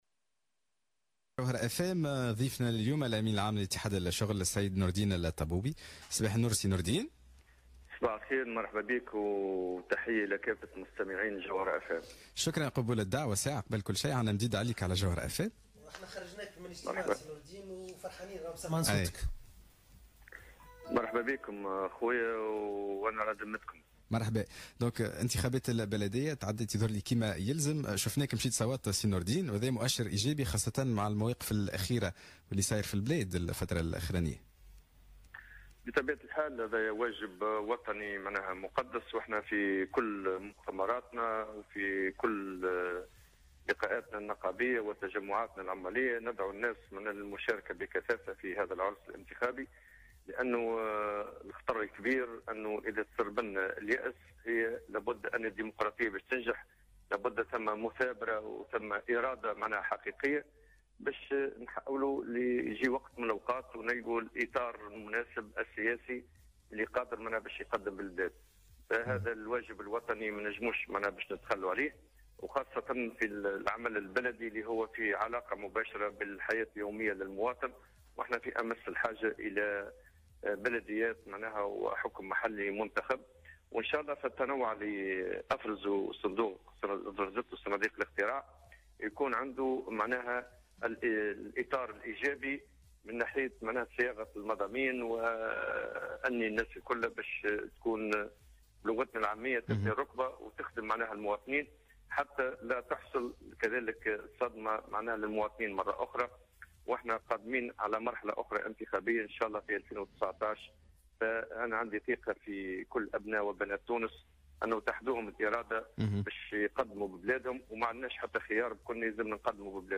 أكد الأمين العام لاتحاد الشغل نورالدين الطبوبي في مداخلة له على الجوهرة "اف ام" اليوم الثلاثاء 8 ماي 2018 أن صياغة وثيقة قرطاج 2 والتي تضمنت أهم الأولويات للفترة المتبقية قد انتهت وينتظرمناقشتها يوم 14 ماي الجاري.